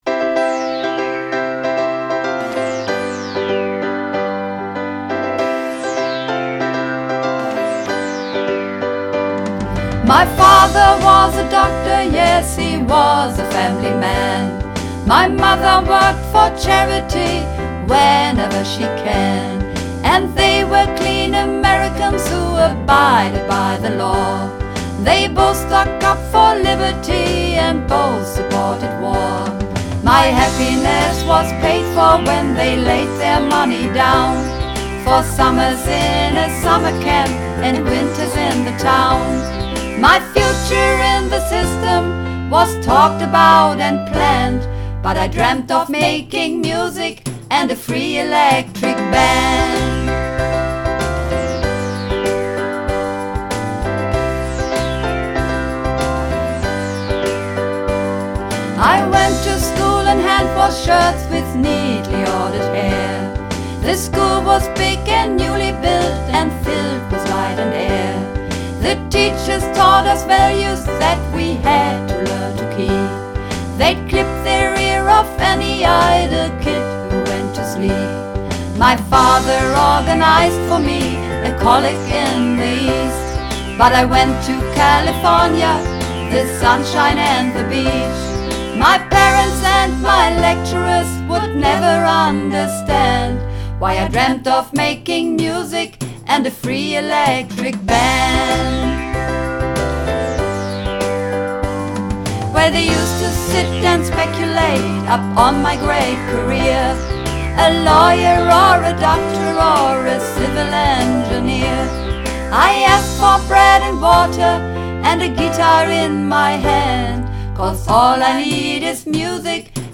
Übungsaufnahmen - The Free Electric Band
Runterladen (Mit rechter Maustaste anklicken, Menübefehl auswählen)   The Free Electric Band (Mehrstimmig)
The_Free_Electric_Band__4_Mehrstimmig.mp3